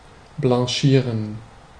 Ääntäminen
IPA: /blɑ̃.ʃiʁ/